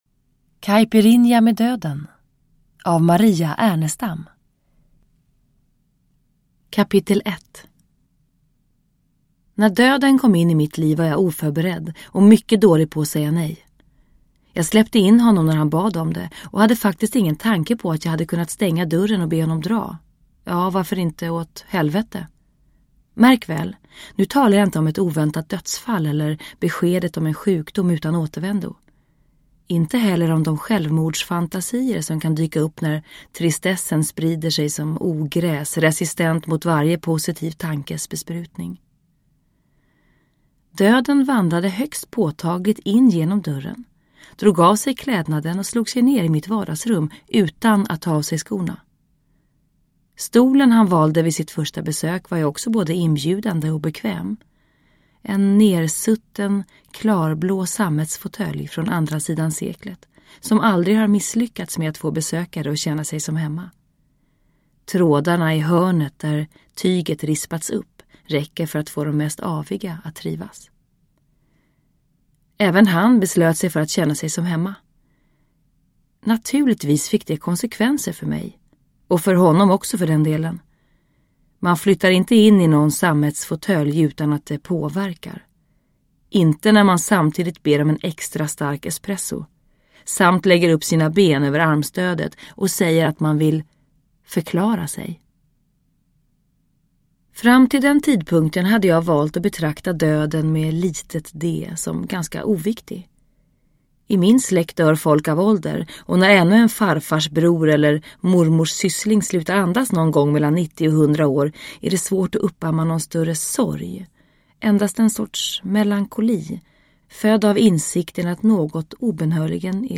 Uppläsare: Eva Röse
Ljudbok